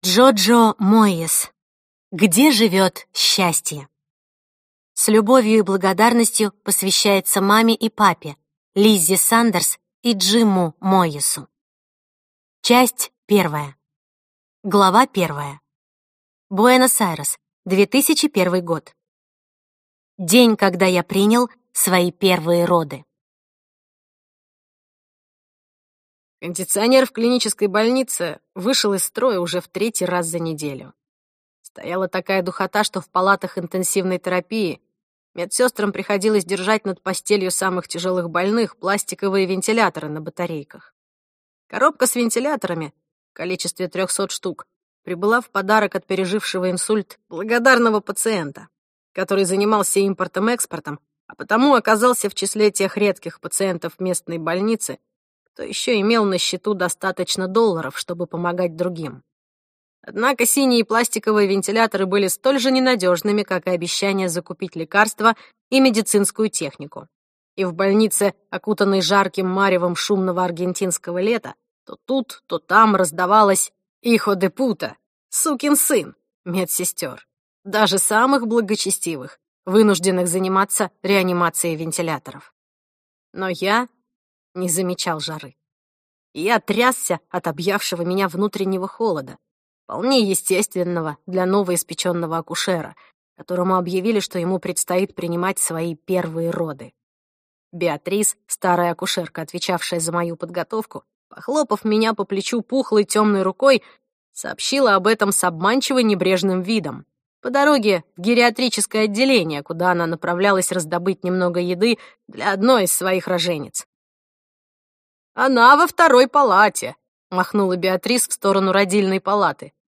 Аудиокнига Где живет счастье - купить, скачать и слушать онлайн | КнигоПоиск